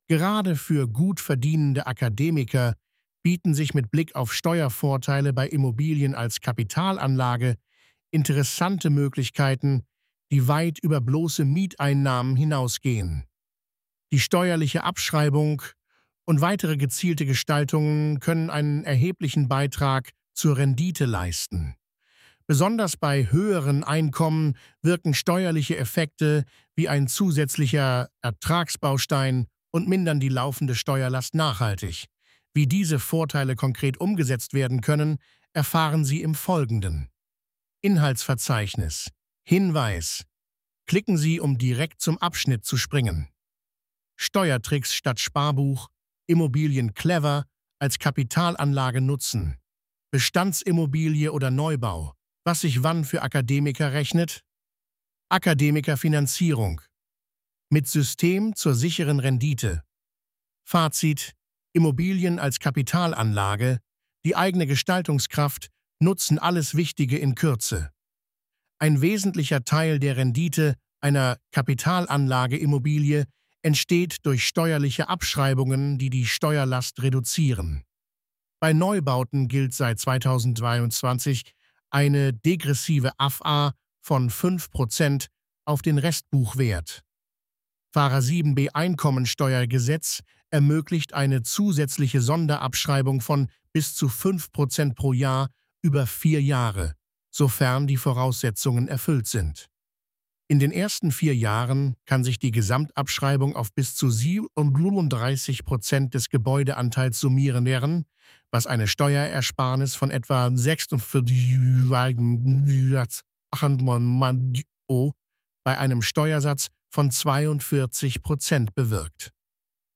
Lassen Sie sich den Artikel von mir vorlesen.